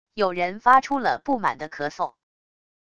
有人发出了不满的咳嗽wav音频